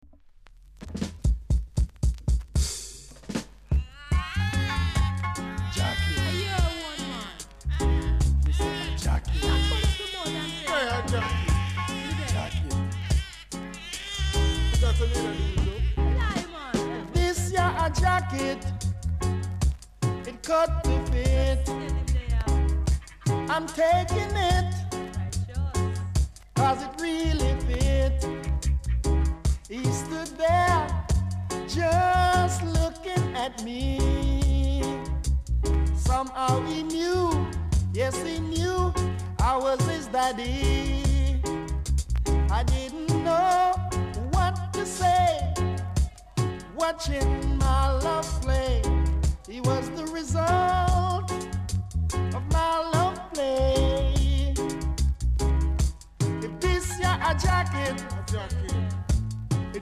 ※イントロで軽くノイズあります。ほか小さなチリノイズが少しあります。盤は薄い擦り傷が少しありますがキレイな方です。